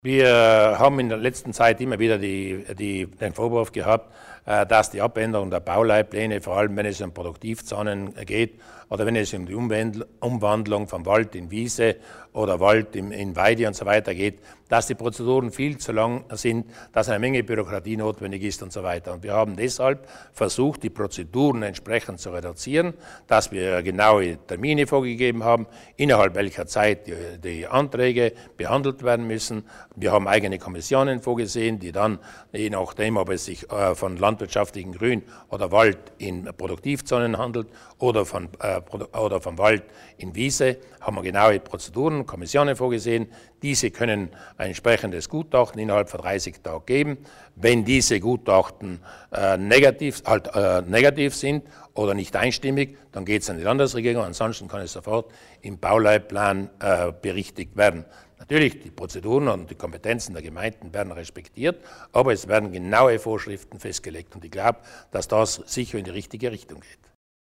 Landeshauptmann Durnwalder erklärt die wichtigen Neuheiten beim Bürokratieabbau